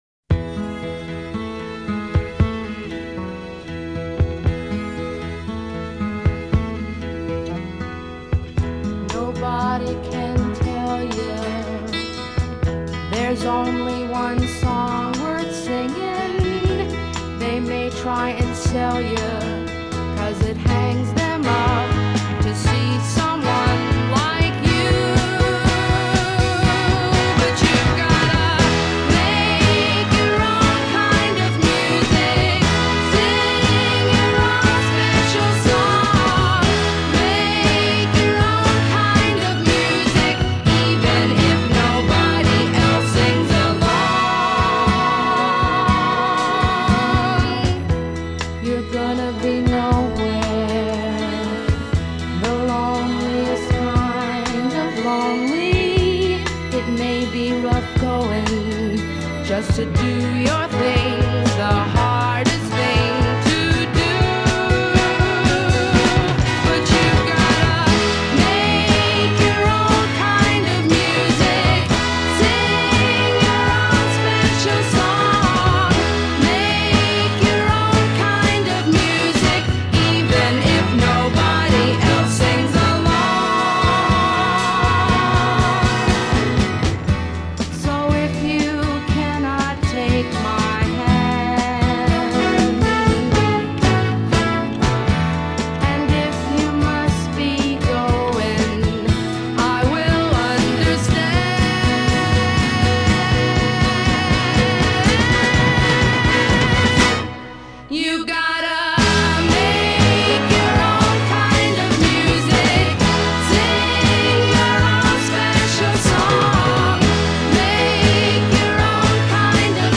Upbeat Records
this happy, upbeat song